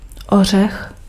Ääntäminen
UK : IPA : /nʌt/ US : IPA : /nʌt/